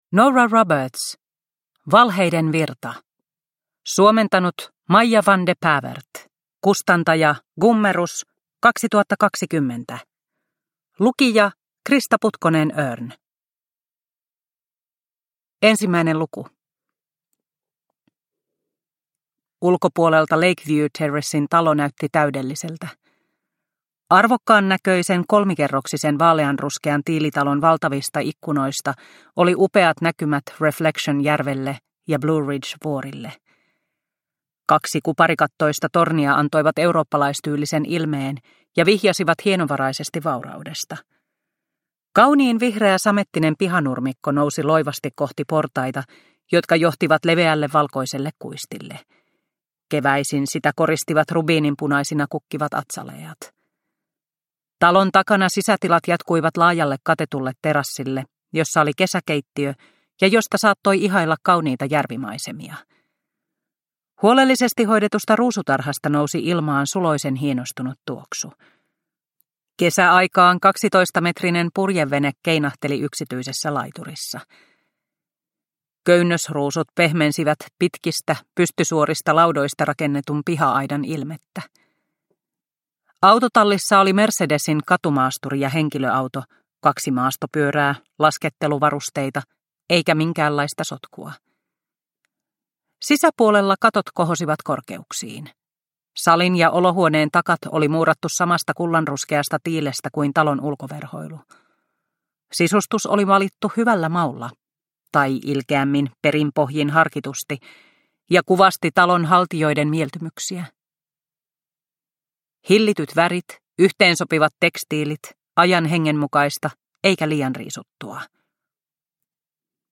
Valheiden virta – Ljudbok – Laddas ner